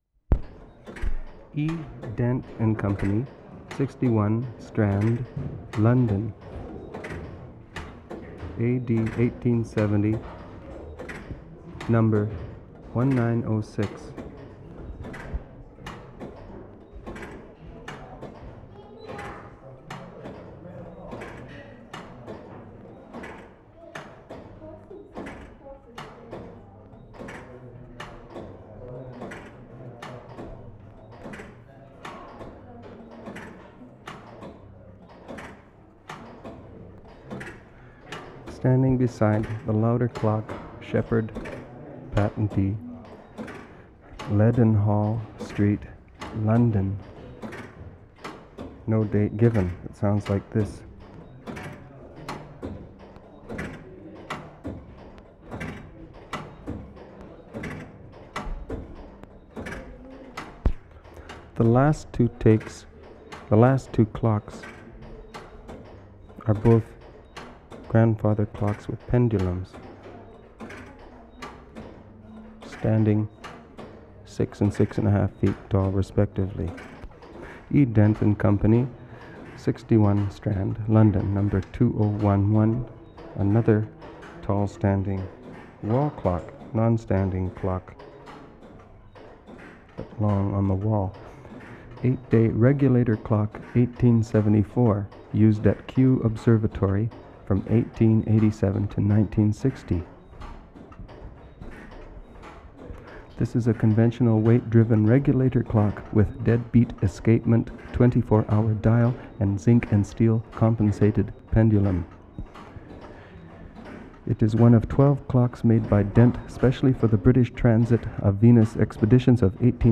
WORLD SOUNDSCAPE PROJECT TAPE LIBRARY
London, England April 27/75
CLOCK EXPLANATIONS READ ALOUD
mark * description and sound of atomic clock. [7:22]
Spoken descriptions of clock operations take on the qualities of the mechanical background itself.